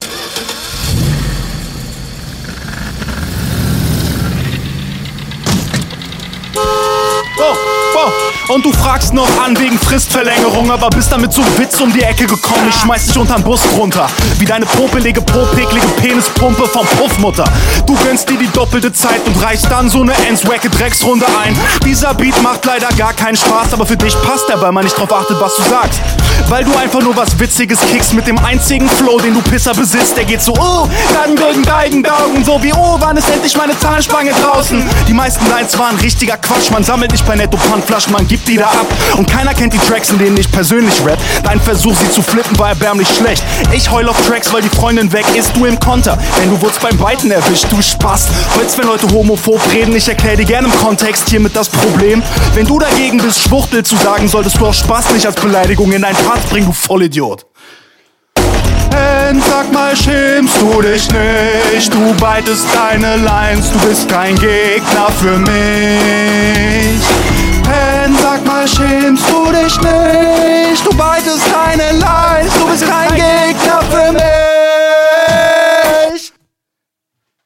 Flow klingt bei dir sehr sicher.
Flowlich top in dieser Runde, Gegnerbezug war definitiv da und puncht.